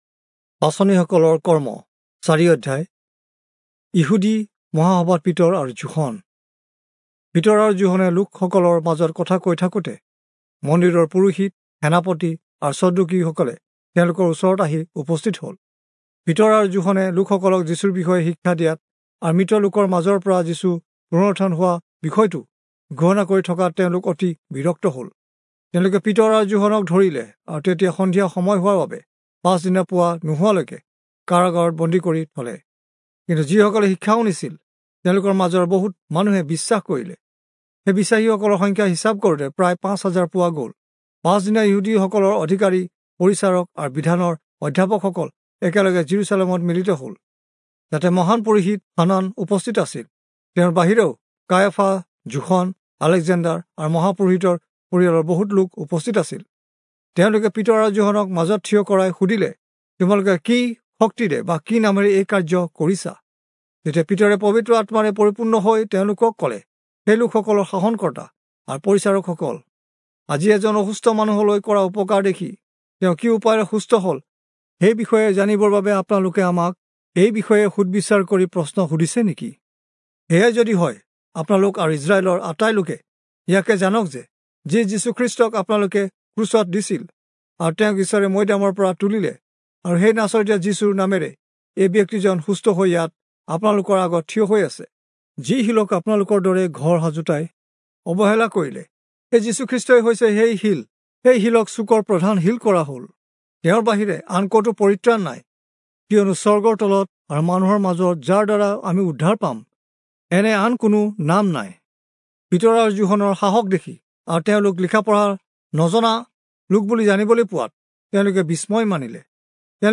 Assamese Audio Bible - Acts 7 in Mhb bible version